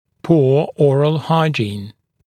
[puə ‘ɔːrəl ‘haɪʤiːn] [pɔː][пуа ‘о:рэл ‘хайджи:н] [по: ]плохая гигиена полости рта, плохой уход за полостью рта